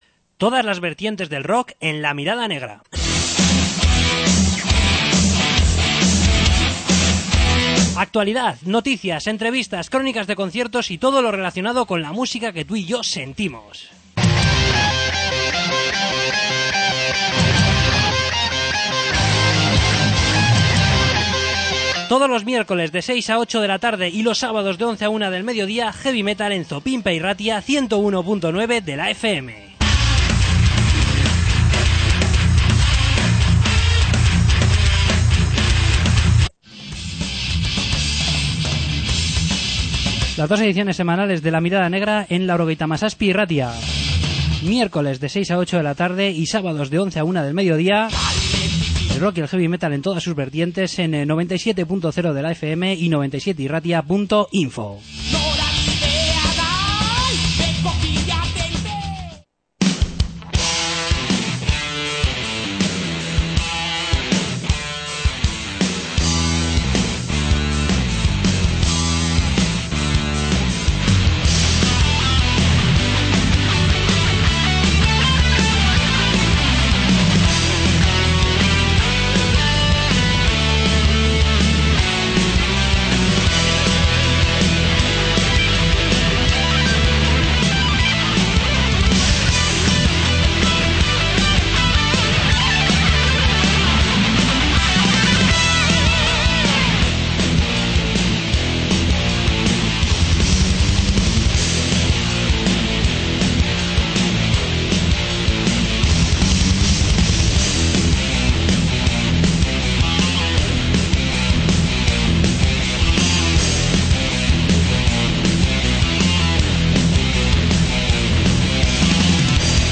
Entrevista con UTM